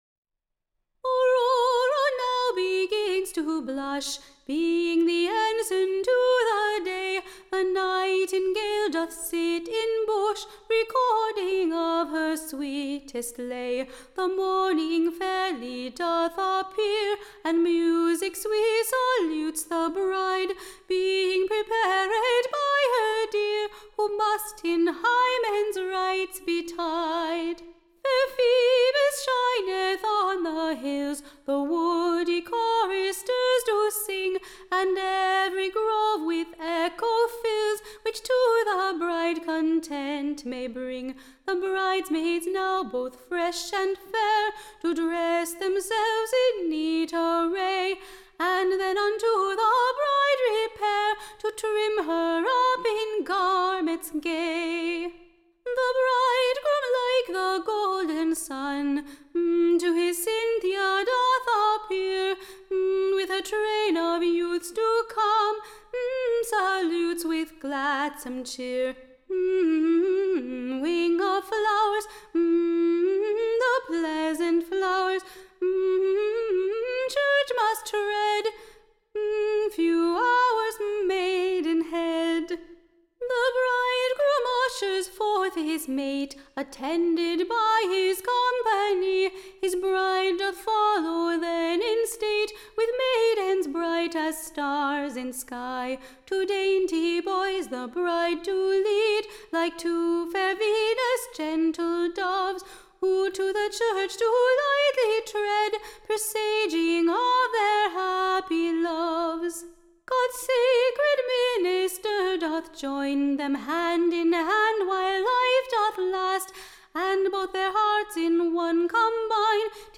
Recording Information Ballad Title [?] / Being a pleasant new Song of the rites and cere- / monies of Mariage.